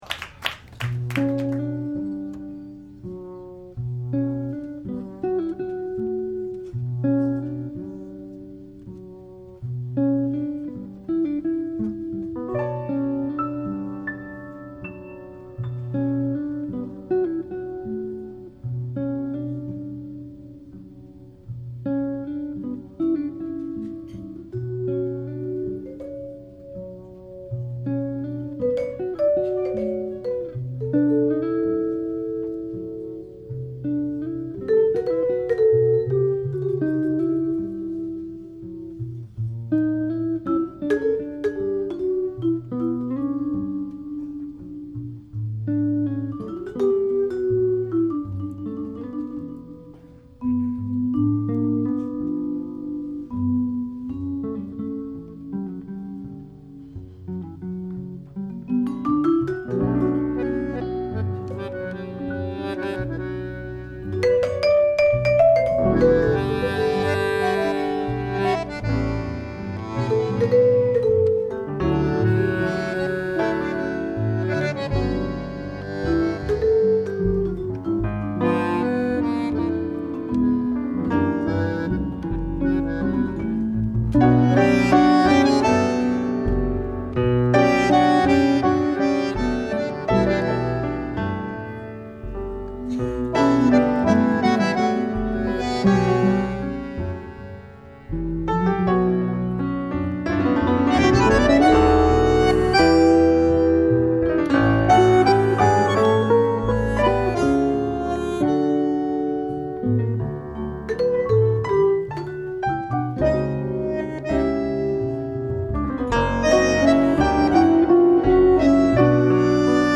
Live at Jazz Standard